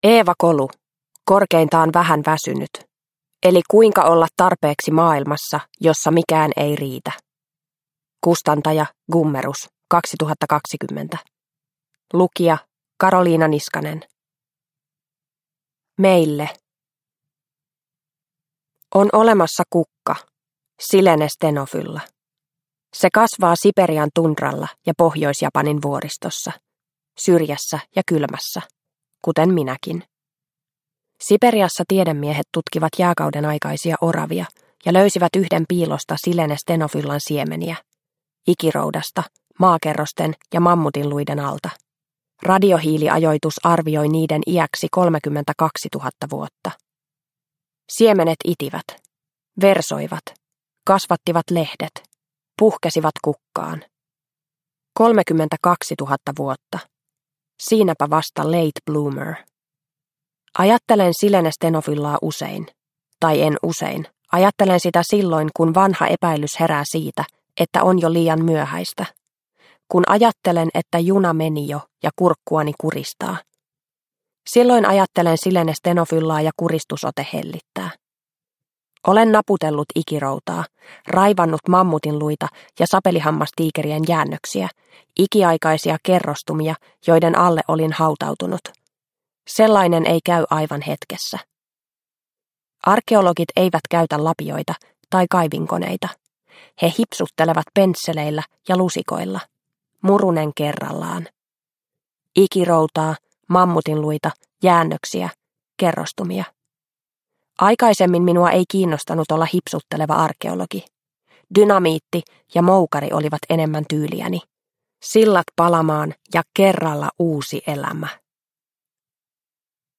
Korkeintaan vähän väsynyt – Ljudbok